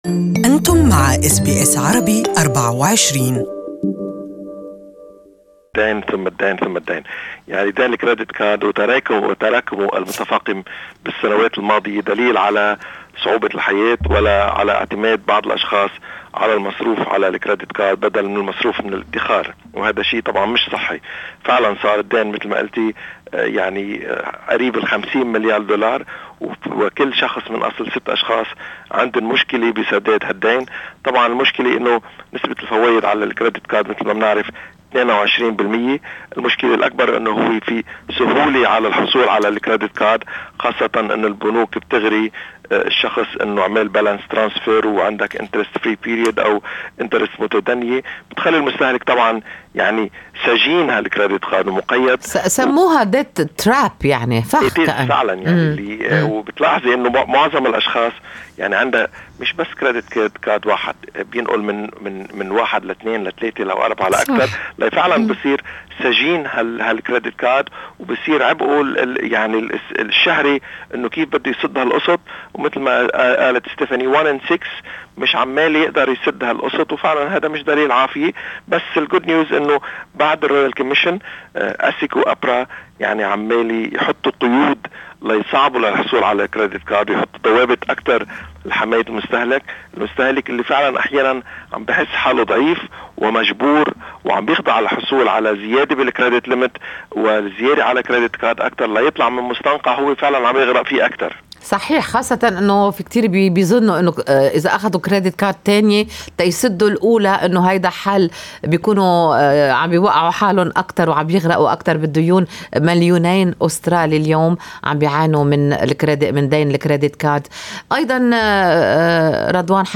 Good Morning Australia interviewed Economist